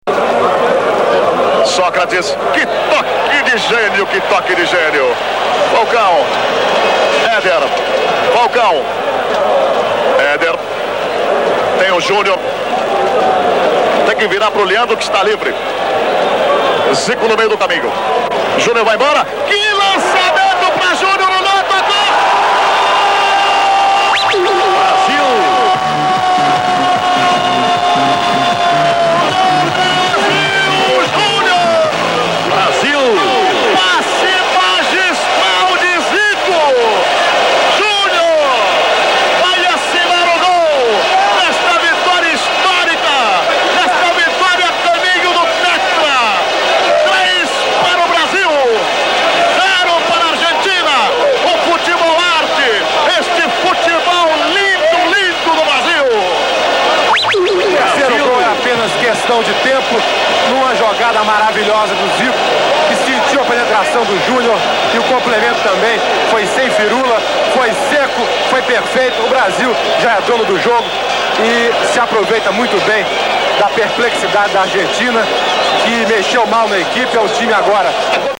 [ Commentaires d'époque ]